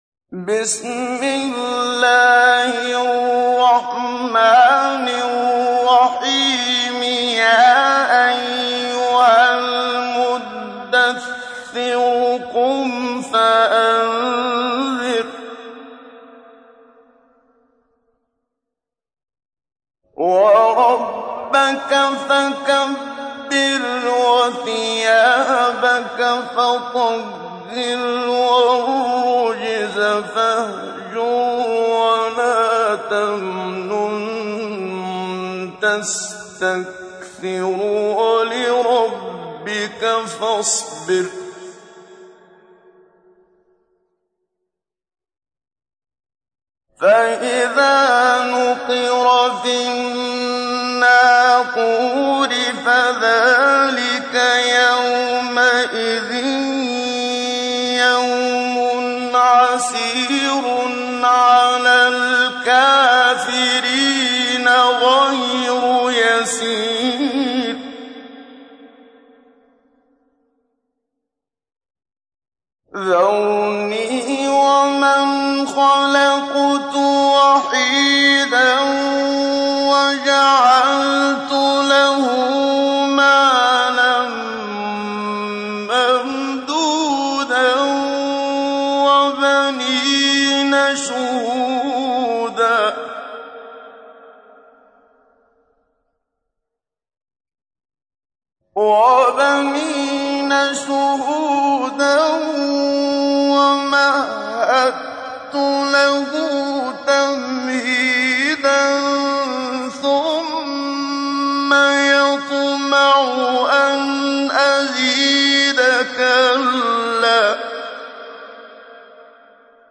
تحميل : 74. سورة المدثر / القارئ محمد صديق المنشاوي / القرآن الكريم / موقع يا حسين